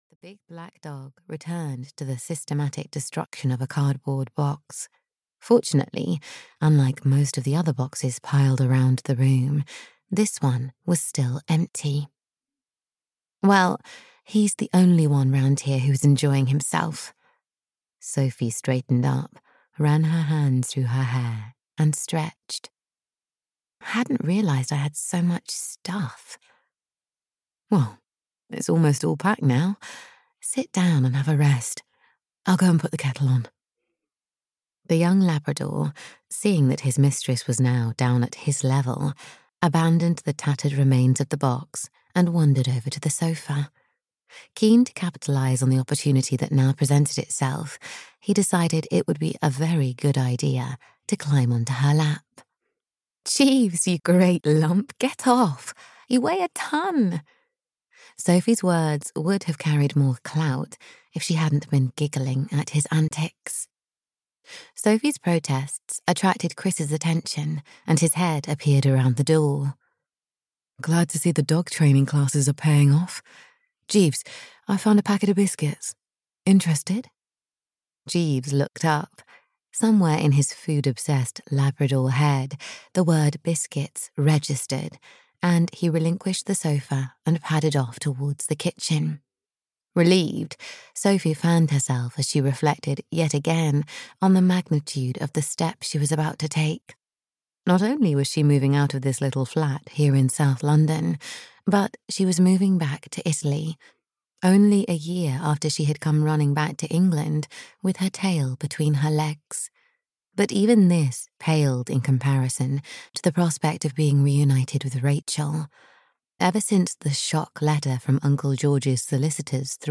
A Little Piece of Paradise (EN) audiokniha
Ukázka z knihy